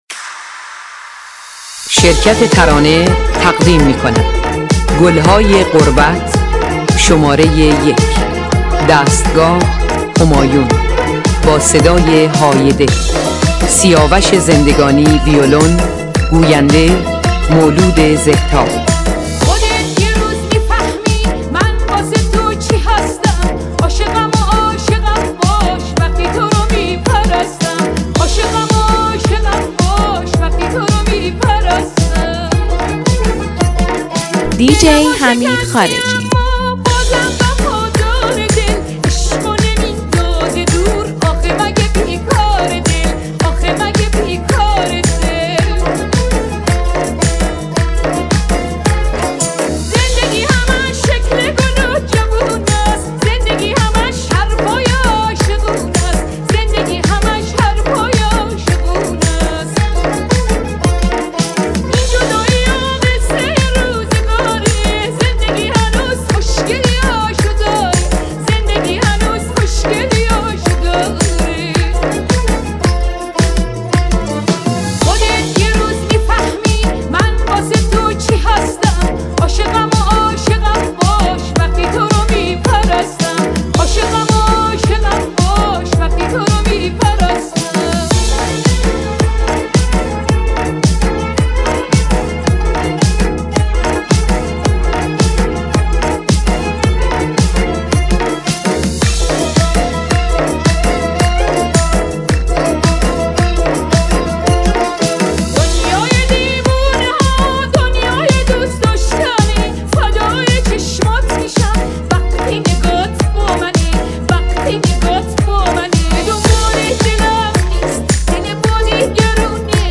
یه ریمیکس زیرخاکی و فوق‌العاده